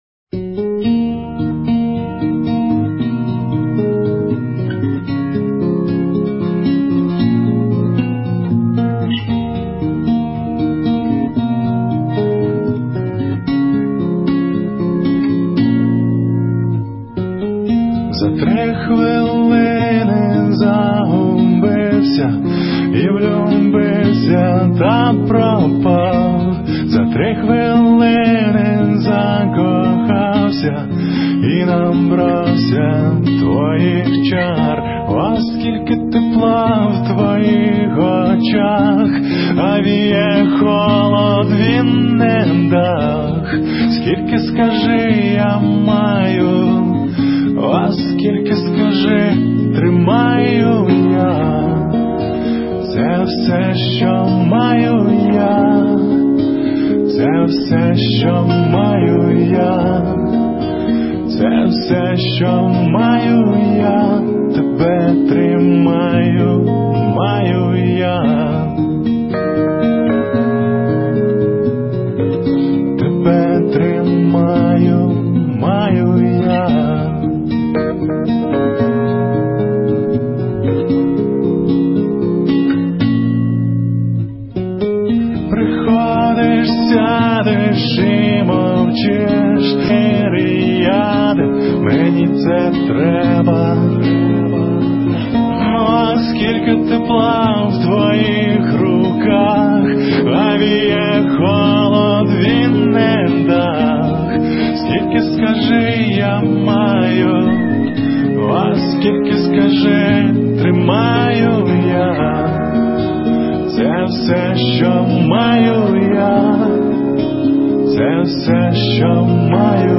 Легкая музыка...